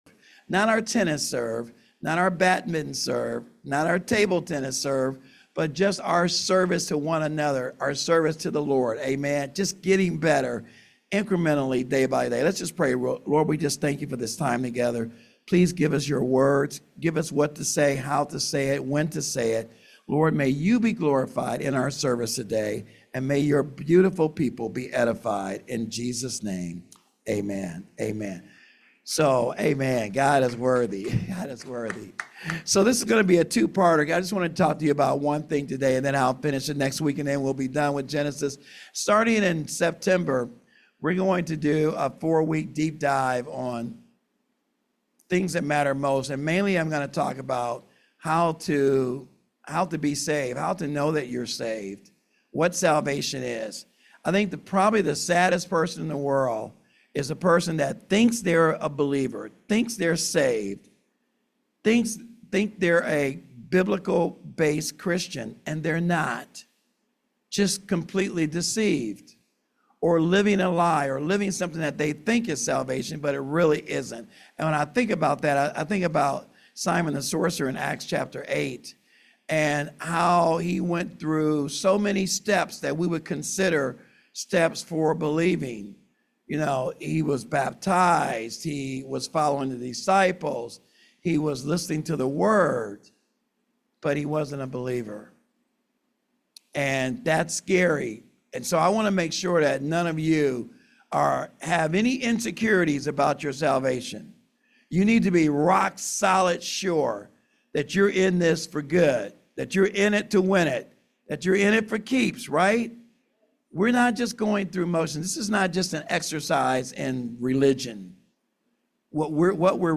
Sermon Handout